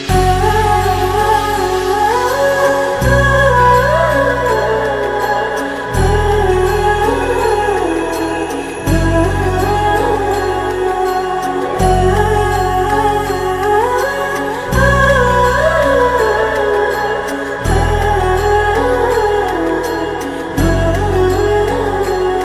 peaceful devotional instrumental
clear HD sound